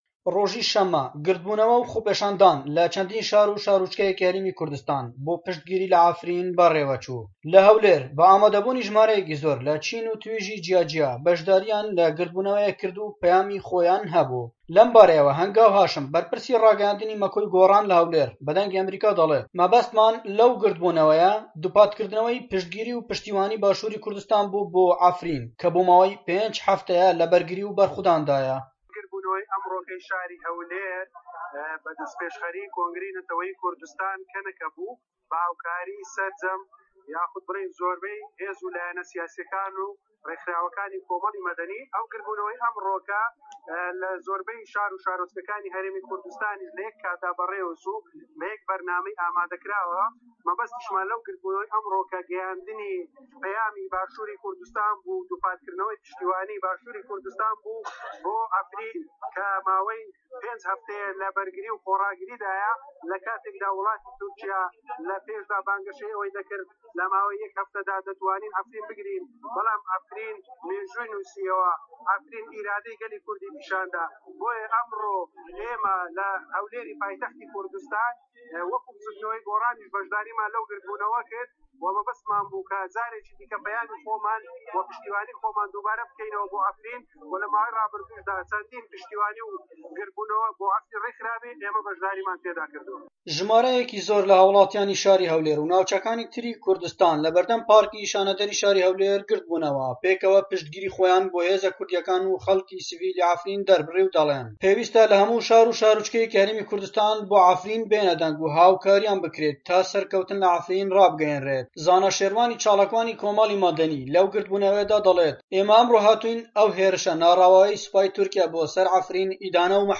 ڕاپۆرت - پشتیوانی لە عەفرین